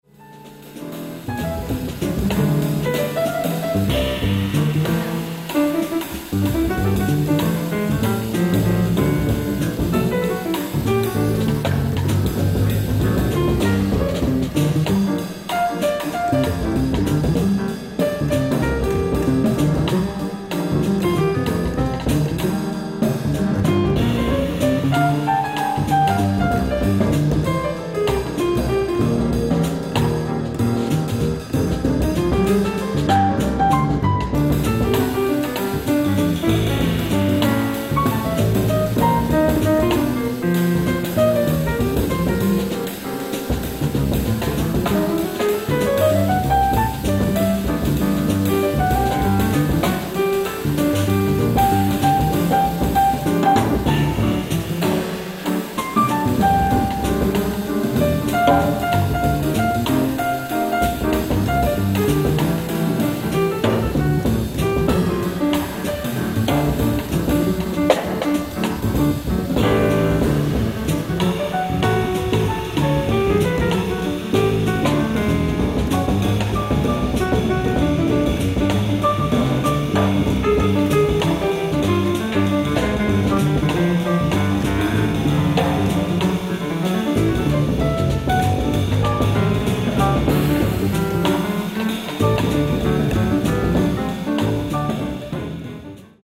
ライブ・アット・ビング・コンサートホール、スタンフォード大学、カリフォルニア 04/02/2025
新トリオによる最新ライブ！！
※試聴用に実際より音質を落としています。